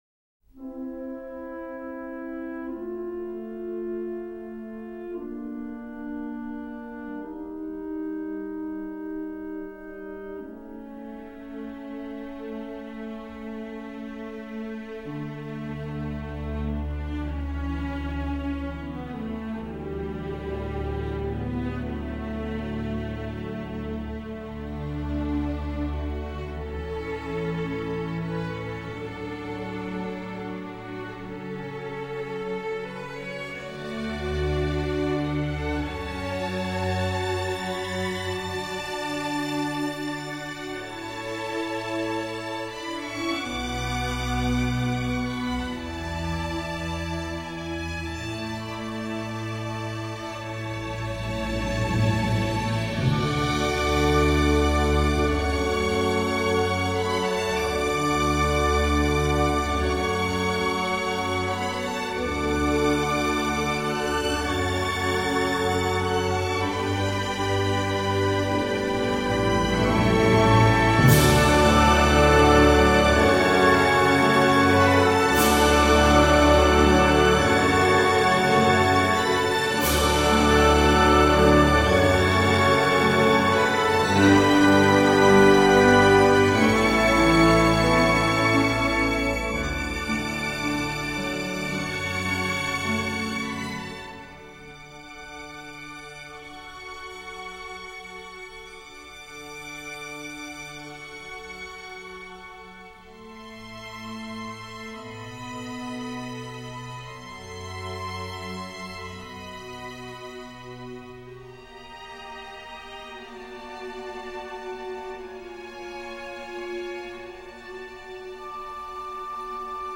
Jolie partition, charmante même
Bref, un bonbon au goût d’autrefois, avec supplément d’âme.
baignant dans une nostalgie 50’s remarquable